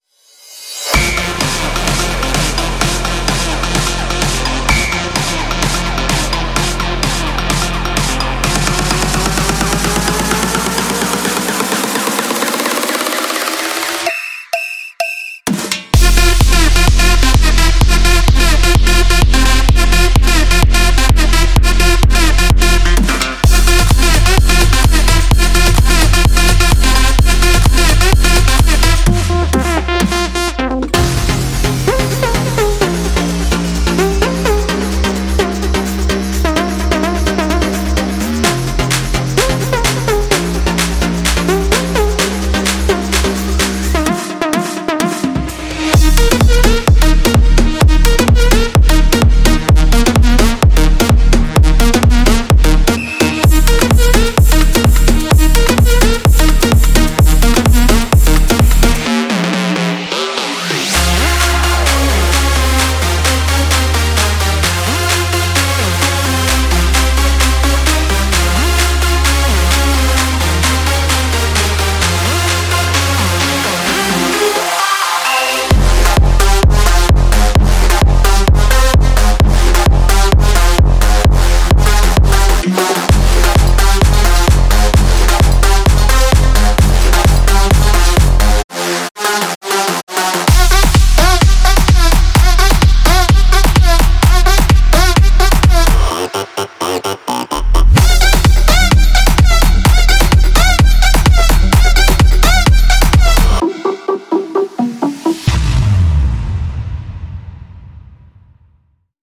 House音色预置
欢迎来到《揭秘巴西》第1;融合了巴西的传统和文化声音以及“揭示的“声音。
无论您是要寻找踢板，军鼓，汤姆斯，FX，令人印象深刻的打击乐部分，还是各种各样的打击乐曲目